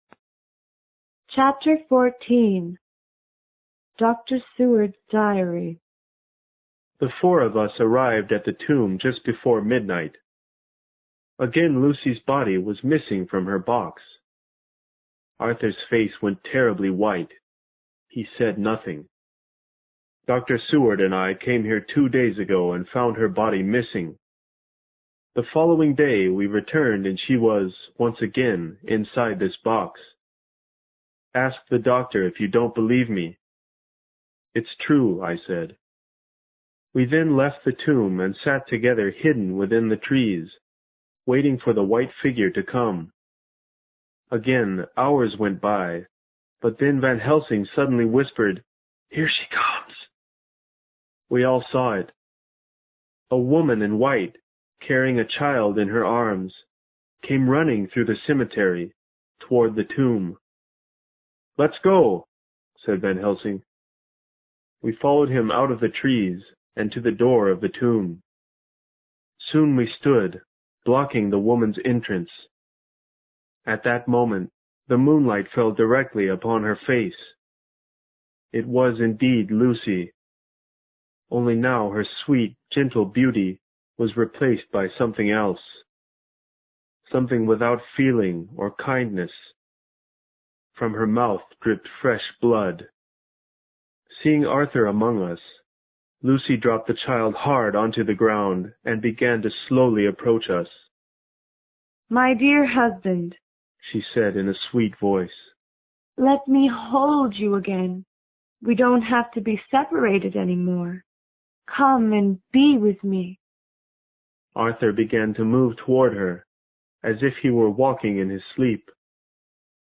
有声名著之吸血鬼 Chapter14 听力文件下载—在线英语听力室